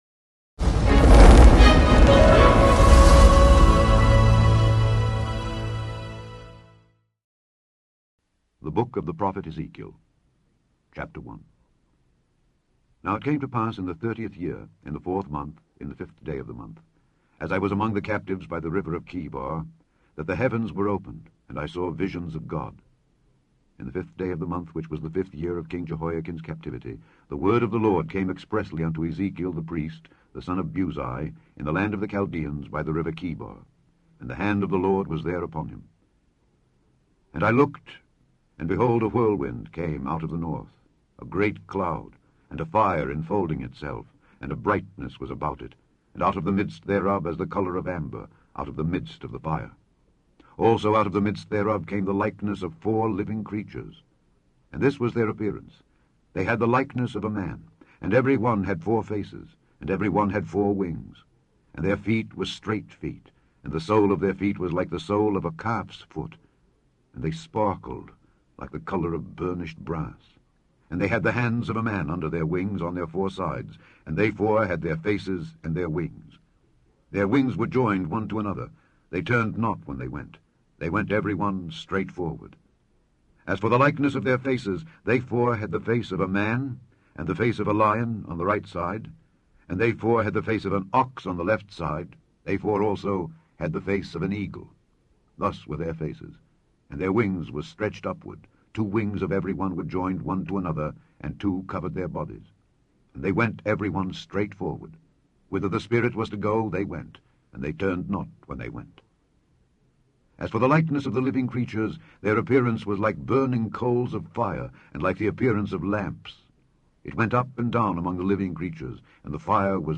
In this podcast, you can listen to Alexander Scourby read Ezekiel 1-6 to you.